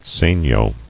[se·gno]